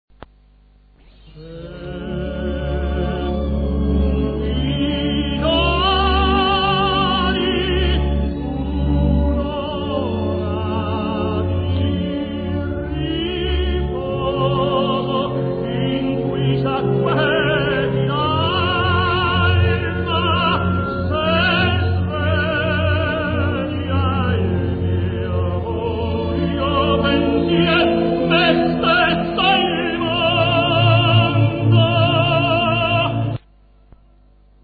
Il brano del Mefistofele e' tratto da alcuni provini effetuati per la Decca prima della incisione dell'opera integrale nella quale pero' appare Del Monaco!!!
lasciando solo qualche provino che ce lo fanno ascoltare in piena forma......e con un po' di rimpainto per l'integrale ....nno andata a buon fine